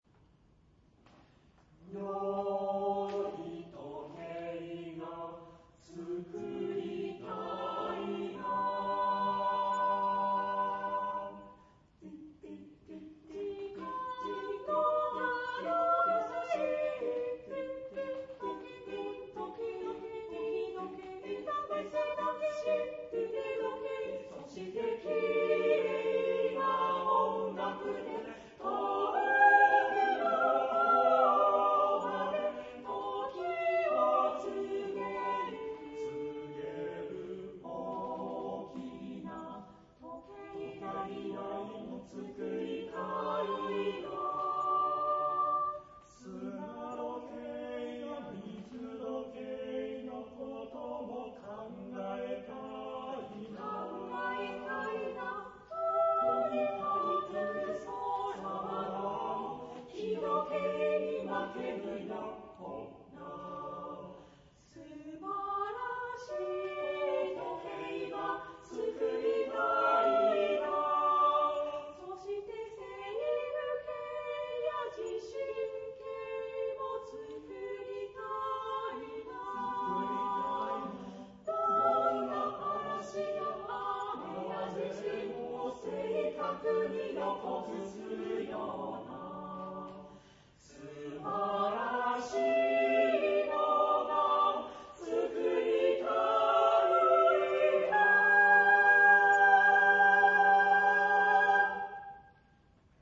第６４回愛知県合唱祭（稲沢市民会館）に出演しました。